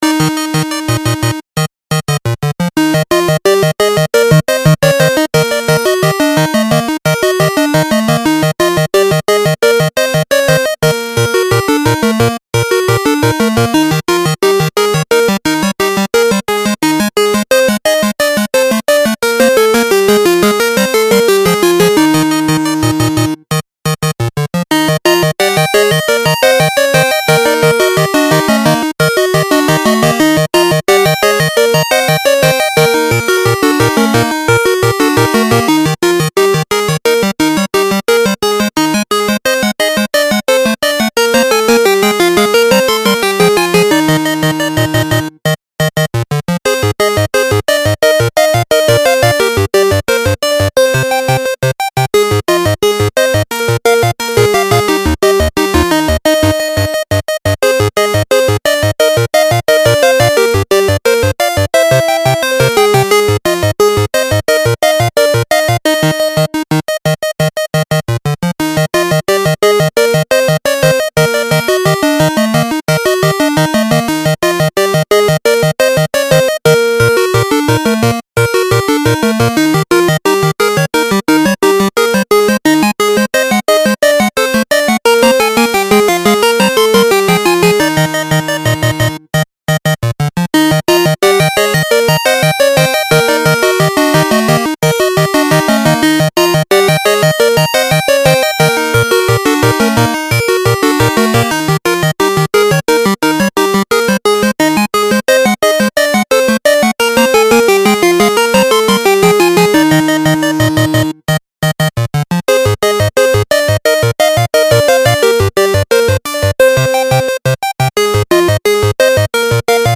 コメディ&アニメ&コミカル系ＢＧＭ
【用途/イメージ】　ゲーム　ファミコン　アニメ　チャレンジ　スポーツ　etc